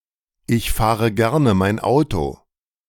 Listen to the two audio clips to compare the pronunciation of “ich fahre” without an Umlaut to “er fährt” with an Umlaut.